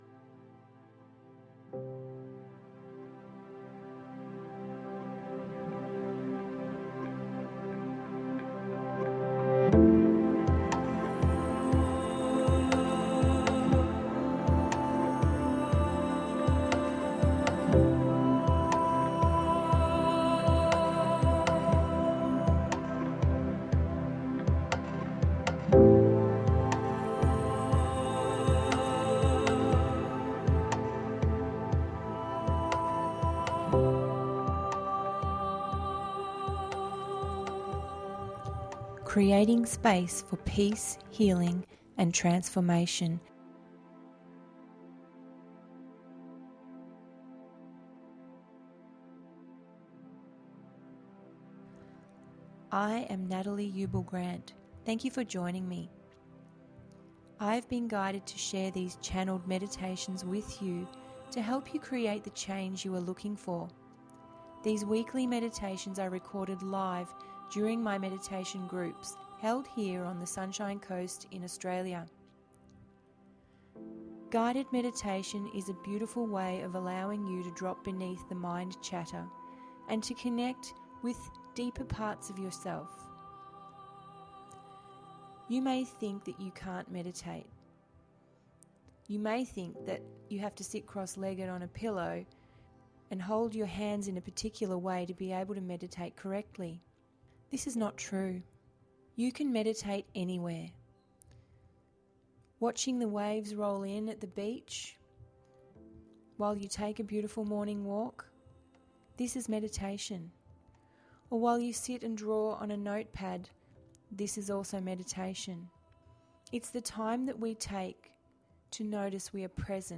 I have been guided to share this Guided meditation that will help you clear away the past. Enjoy the visualisation and be guided today to release the emotions of the past that have been locked away. Re-connect with the younger version of you that has been disconnected for some time.